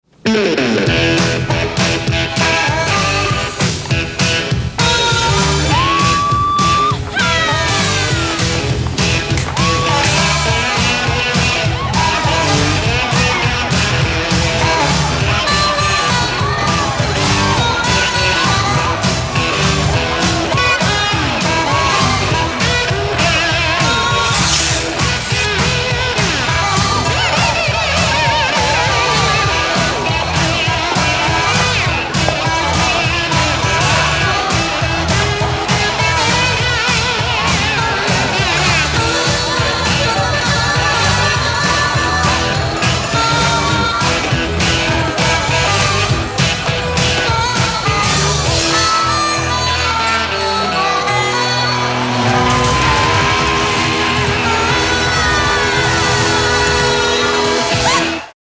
• Качество: 320, Stereo
инструментальные
ретро
блюз
рок
guitar